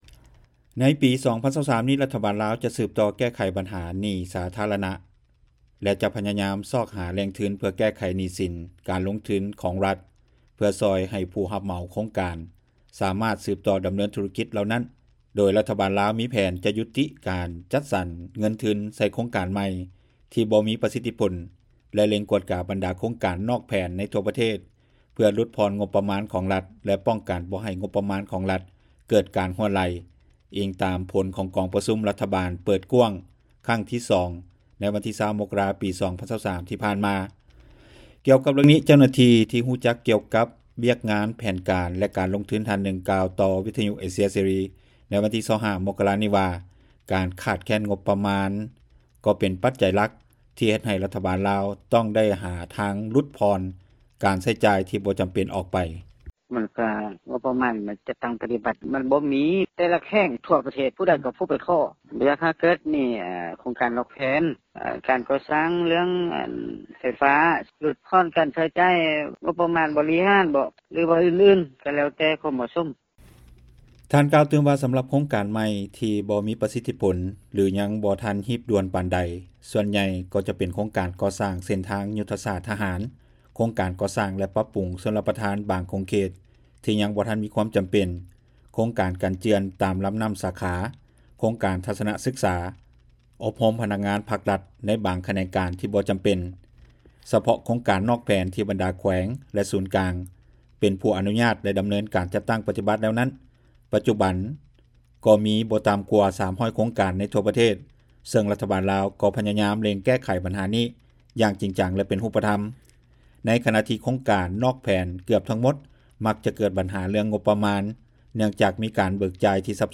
ດັ່ງເຈົ້າໜ້າທີ່ ກະຊວງການເງິນ ທ່ານນຶ່ງກ່າວຕໍ່ວິທຍຸ ເອເຊັຽ ເສຣີ ໃນມື້ດຽວກັນນີ້ວ່າ:
ດັ່ງຊາວລາວທ່ານນຶ່ງ ໃນແຂວງຈຳປາສັກ ກ່າວຕໍ່ວິທຍຸ ເອເຊັຽ ເສຣີ ໃນມື້ດຽວກັນນີ້ວ່າ:
ດັ່ງຊາວລາວທ່ານນີ້ ກ່າວຕໍ່ວິທຍຸ ເອເຊັຽ ເສຣີ ໃນມື້ດຽວກັນນີ້ວ່າ: